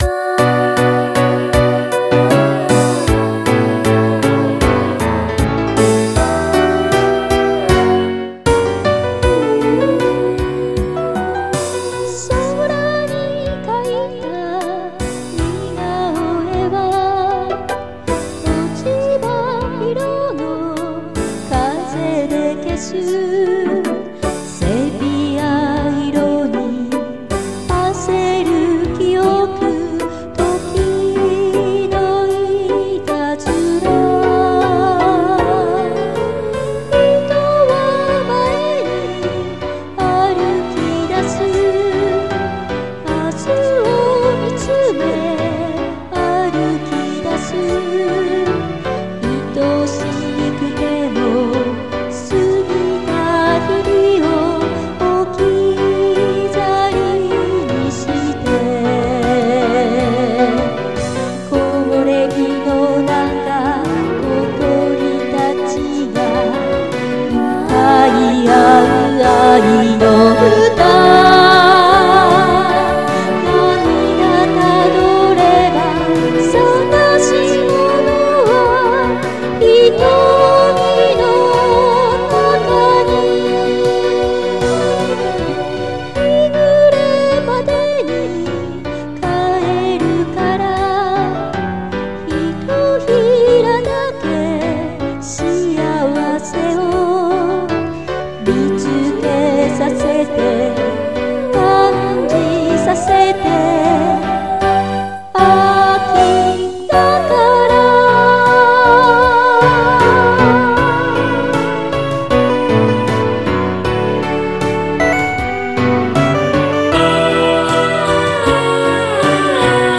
秋らしく侘びしくロマンチックで、ドラマチックな歌です。